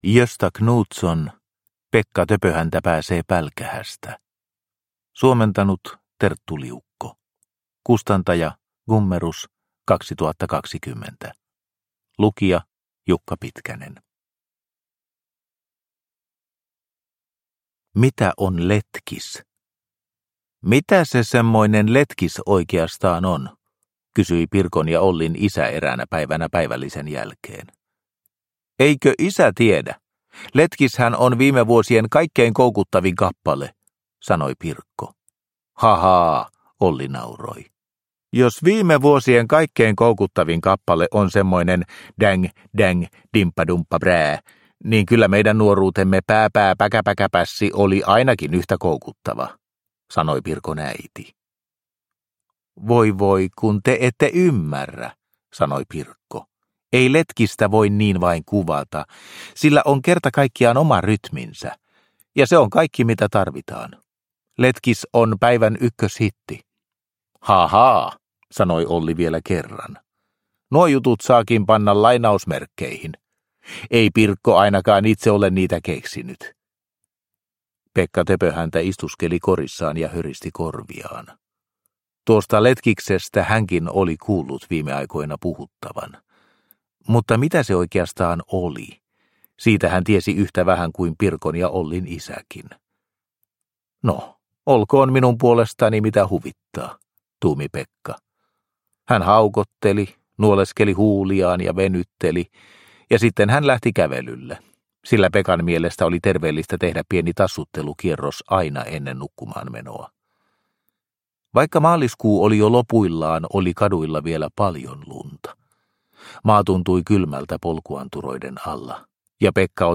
Pekka Töpöhäntä pääsee pälkähästä – Ljudbok – Laddas ner